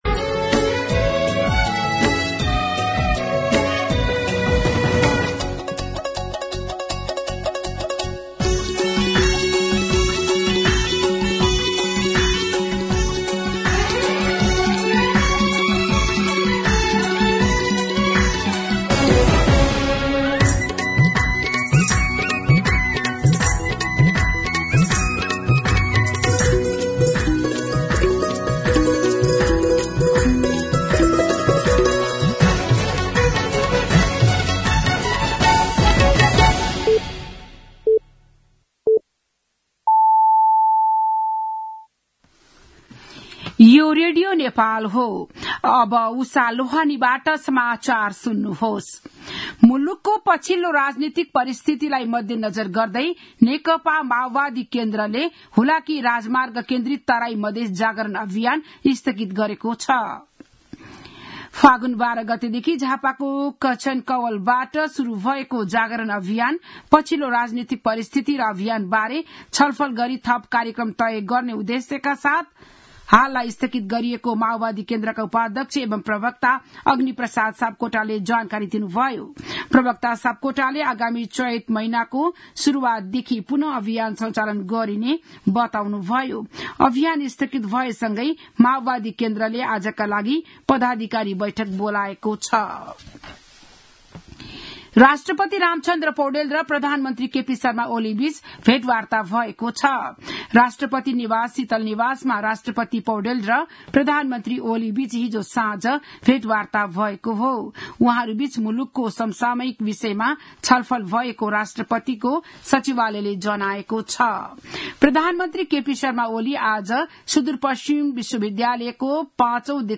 बिहान ११ बजेको नेपाली समाचार : २६ फागुन , २०८१